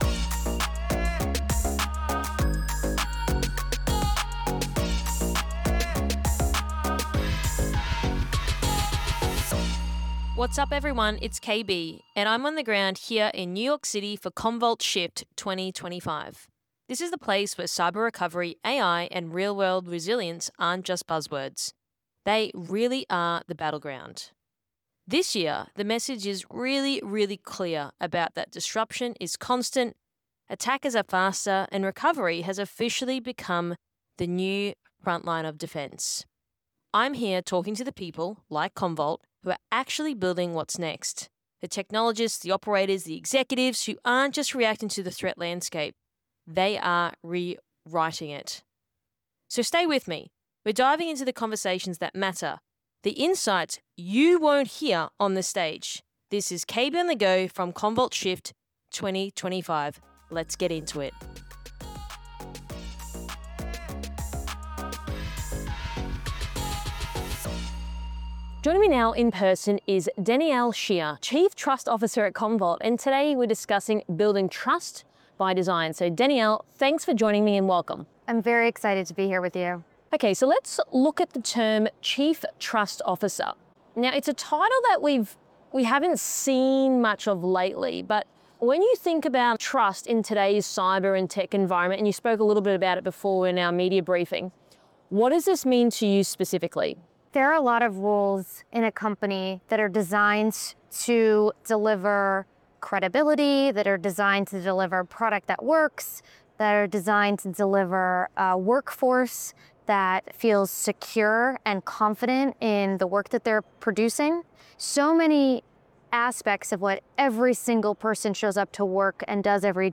From Commvault SHIFT 2025